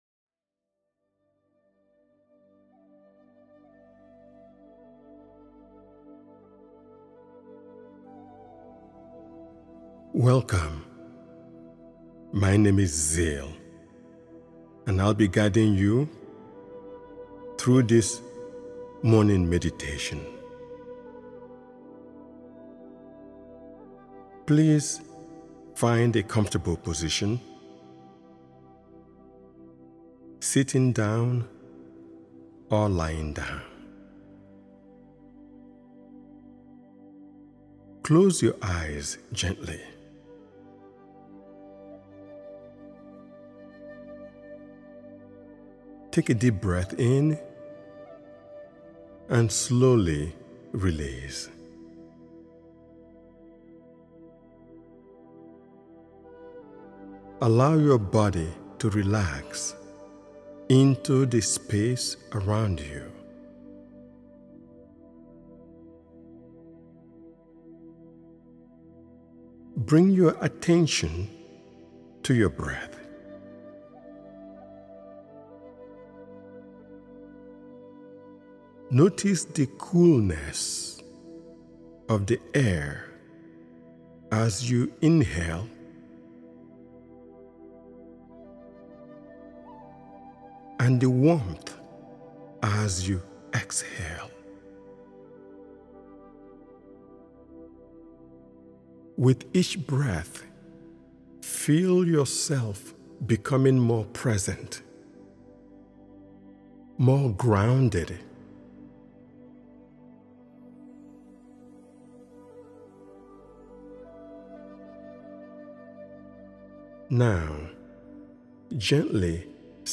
Morning Clarity: Center Your Mind, Energize Your Day is a grounding and restorative guided meditation designed to help you begin each morning with focus, calm, and steady energy.
As attention moves through the body, guided by calm instruction, areas of tension are invited to soften.